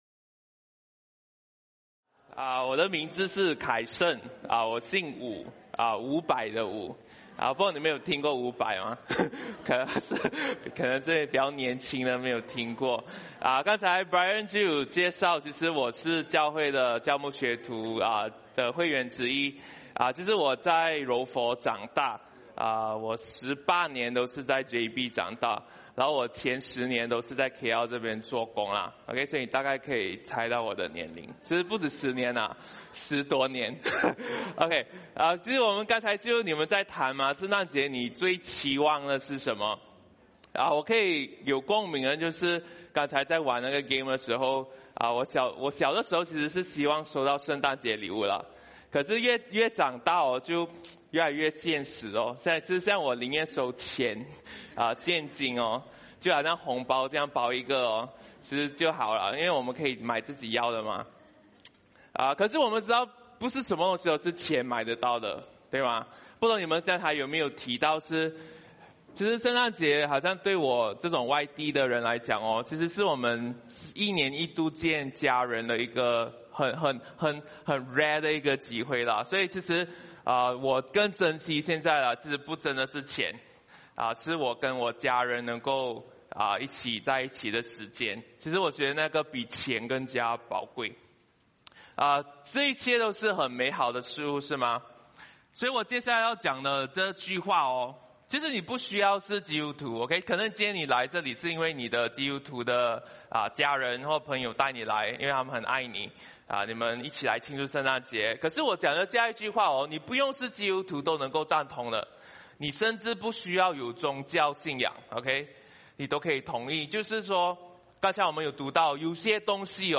Thursday, 25 December 2025 // Christmas at CERC
Download Download MP3 Glossary To help you with unfamiliar terms, check out things you might hear on a Sunday → Please note that all the audio for our sermons is recorded live and only very lightly edited. From time to time you will hear the preacher making off-the-sermon comments or silences that come with this context.